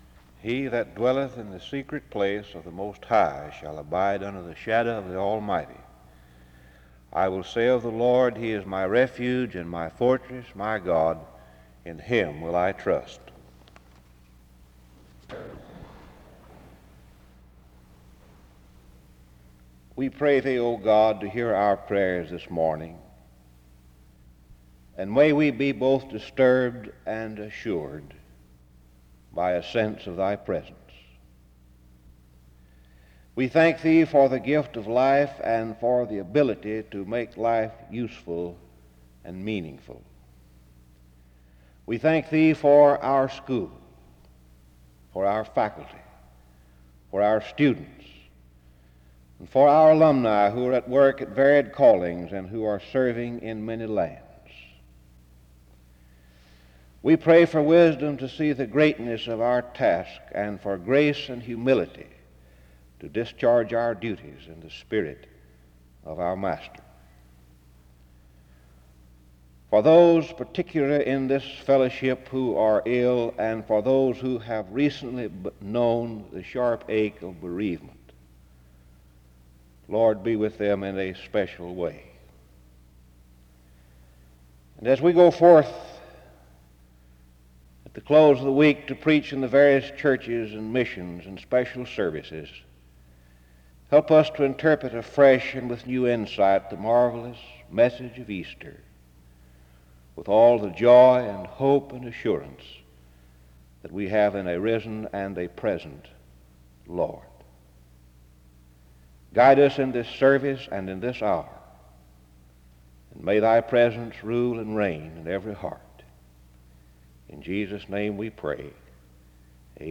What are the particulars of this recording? In Collection: SEBTS Chapel and Special Event Recordings SEBTS Chapel and Special Event Recordings